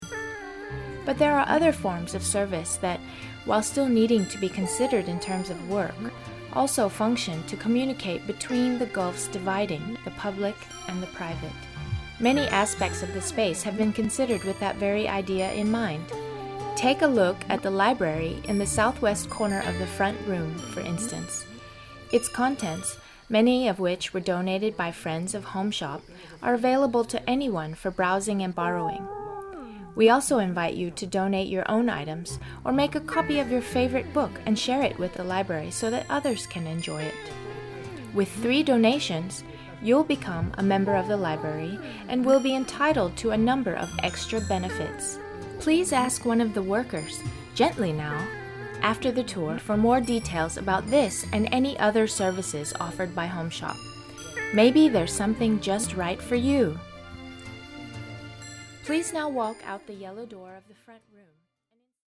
家作坊语音导览英文版摘录 excerpt from the English version of the HomeShop Audio Tour
PROGRAM: Available exclusively on-site at HomeShop, the HomeShop Audio Tour is a new and exciting audio adventure introducing fresh visitors and old friends alike to the story of our would-be institution. All members of the public are welcome to stroll around the grounds at Jiaodaokou Beiertiao No. 8 with a headset, listening to the voices of the past, present and future animating the space.